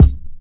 break_kick_12.wav